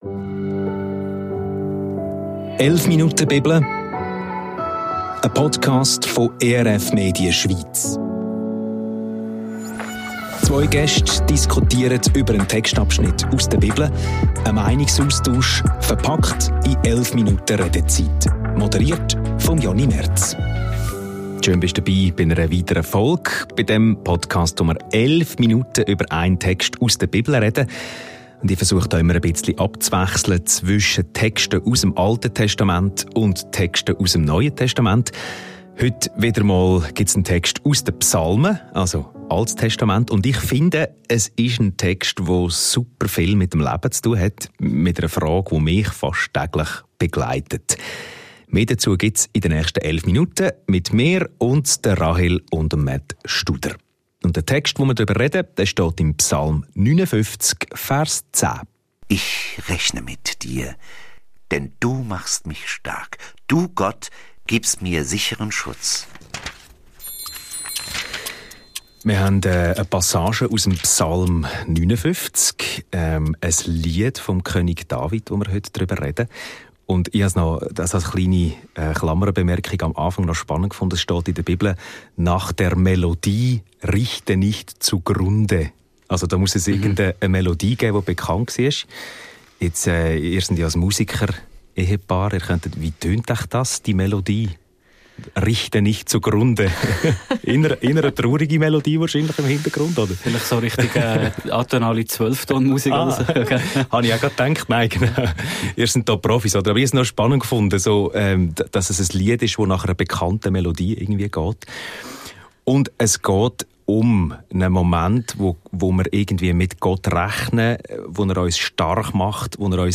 Ein Gespräch, das an der Oberfläche beginnt, dann aber in den (Un-)Tiefen endet.